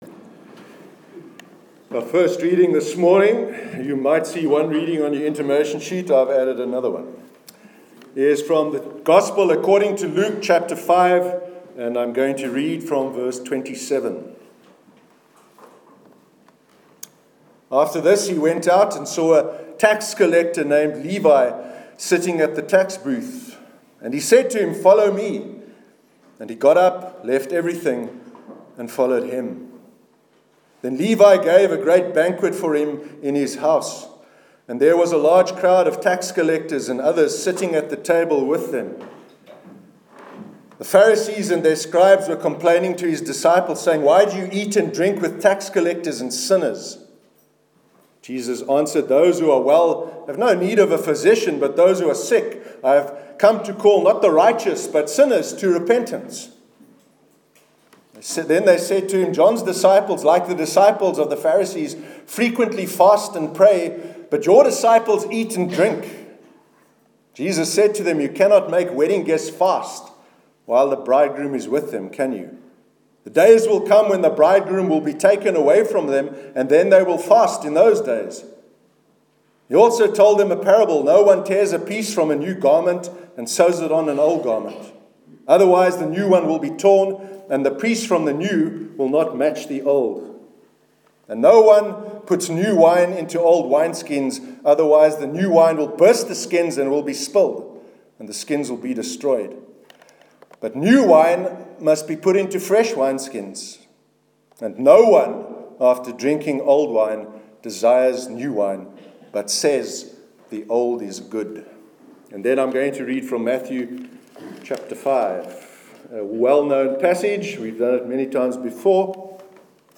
Sermon on God’s Inclusivity- 2nd April 2017
sermon_2nd_april_2017.mp3